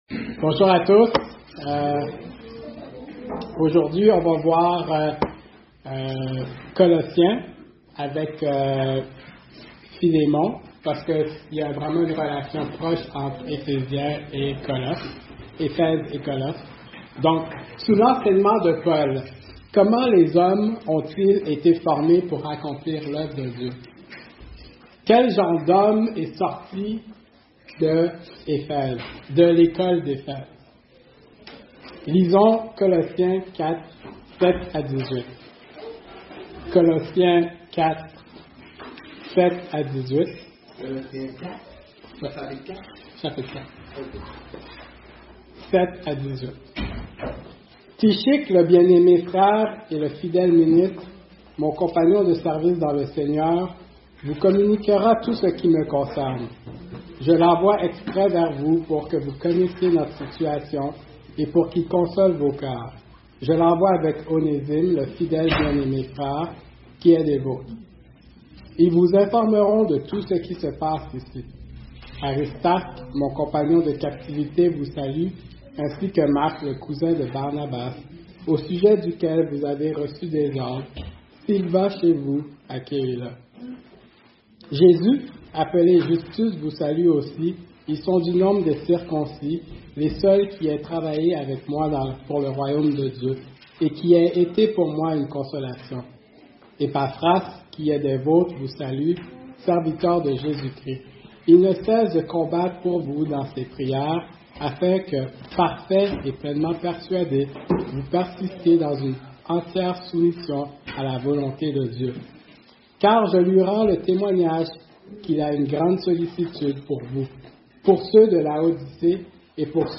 Cours du dimanche matin 8 Décembre 2024 - Genèse 27 - Église Baptiste Évangélique Emmanuel - Podcast